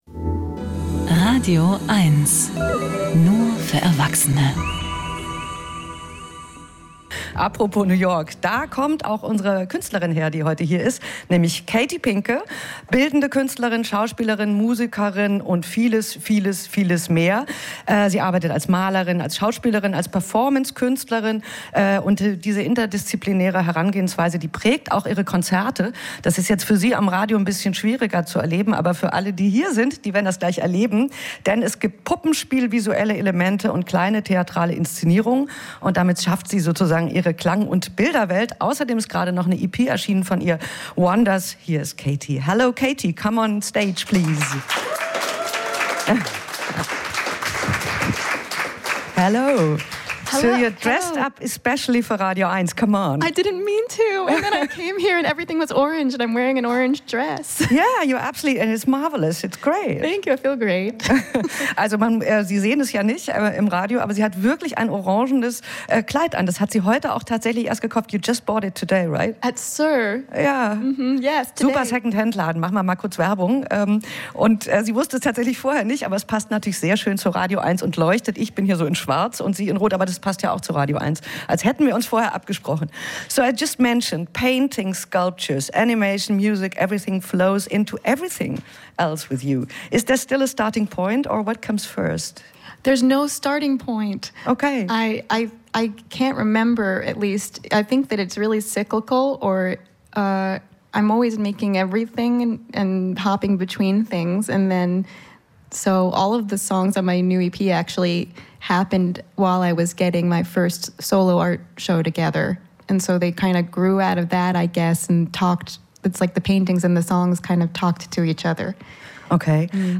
Musik-Interviews Podcast